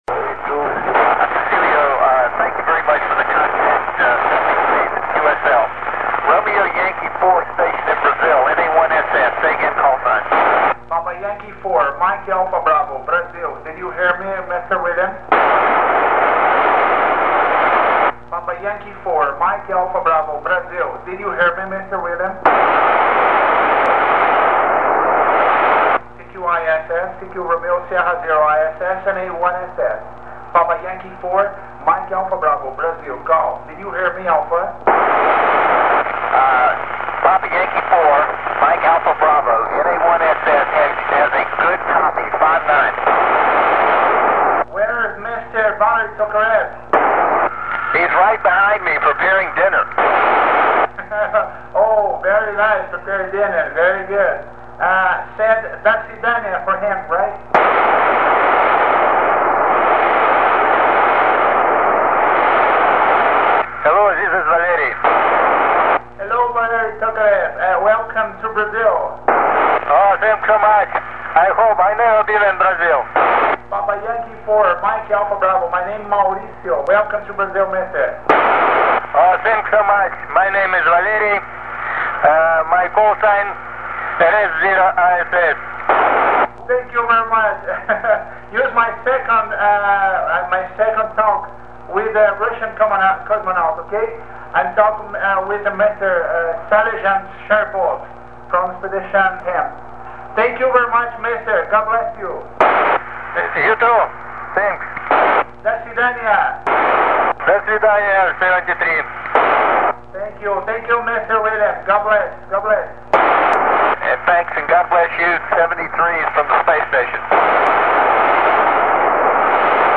VERY NICE CONTACT WITH 2 ASTRONAUTS IN SPACE. 28/11/2005